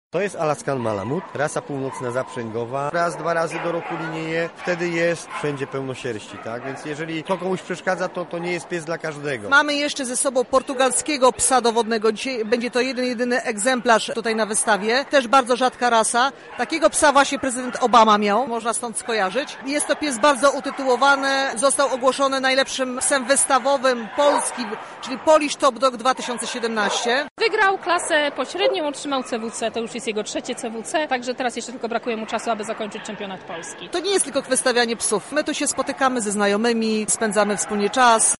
Na miejscu wydarzenia byli nasi reporterzy: